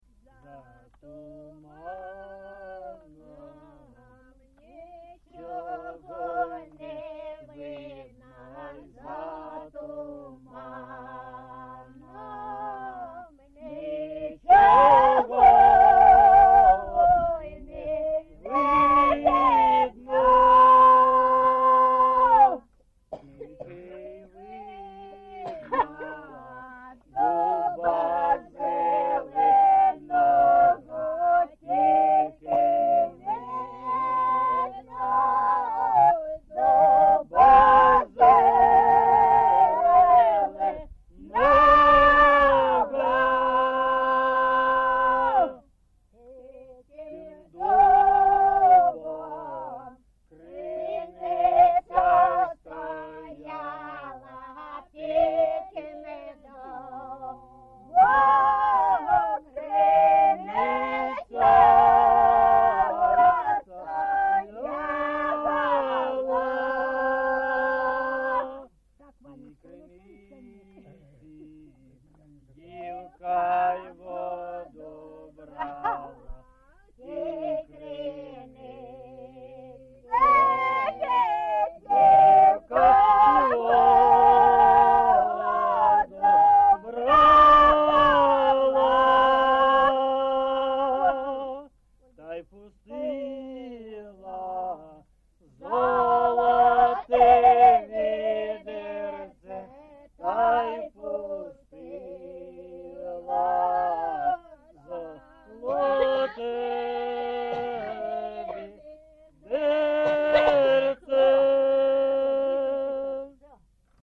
ЖанрПісні з особистого та родинного життя
Місце записум. Дружківка, Краматорський район, Донецька обл., Україна, Слобожанщина